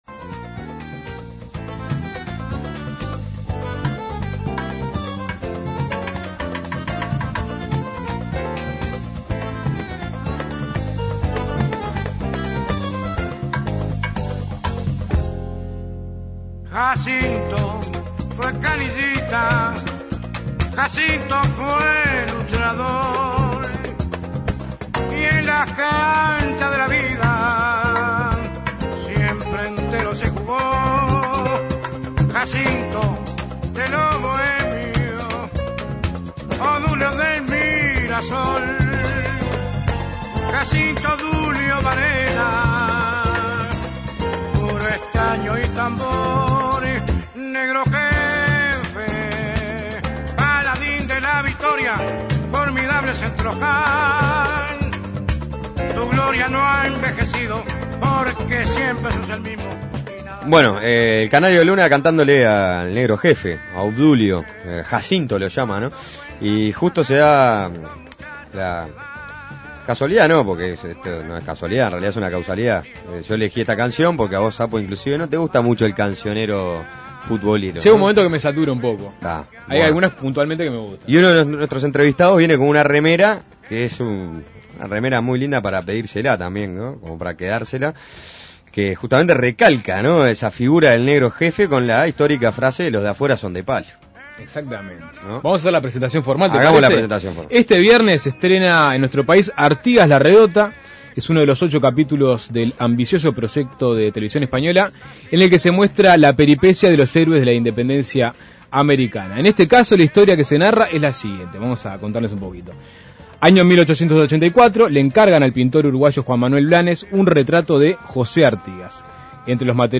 El cineasta encargado de llevar a este personaje de los libros de historia al celuloide, fue el uruguayo César Charlone, quien para sorpresa de muchos, eligió a Jorge Esmoris para representar a Artigas. Suena Tremendo dialogó con ambos.